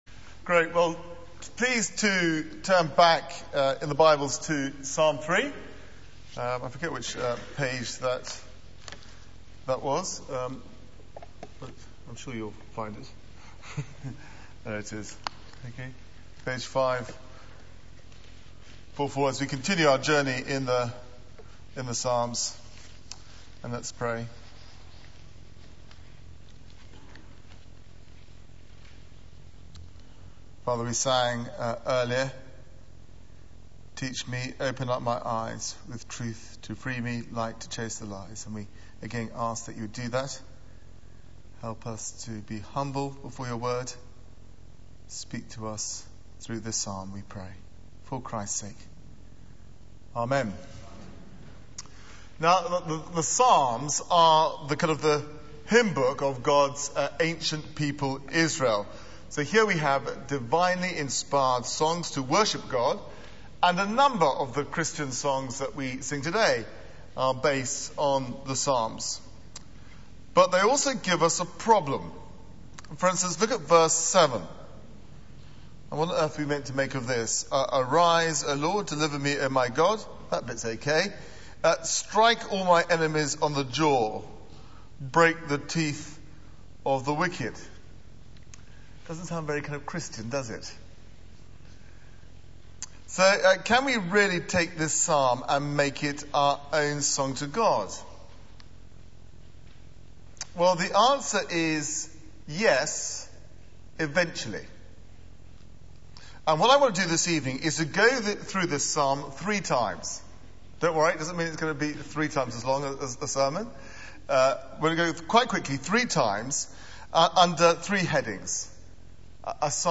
Media for 9:15am Service on Sun 28th Jun 2009 18:30 Speaker: Passage: Psalm 3 Series: Summer Songs Theme: The God who Delivers Sermon Search the media library There are recordings here going back several years.